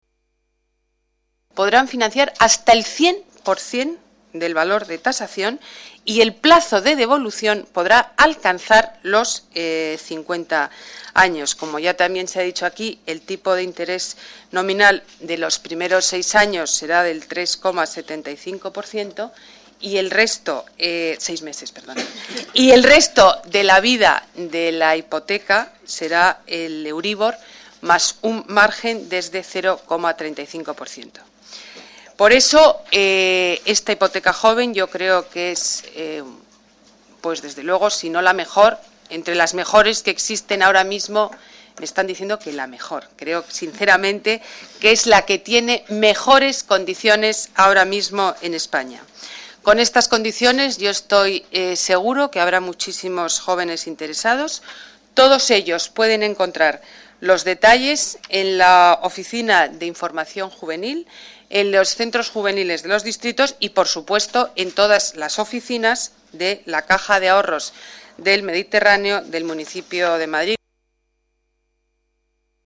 Nueva ventana:Declaraciones de Ana Botella Sobre la Hipoteca Joven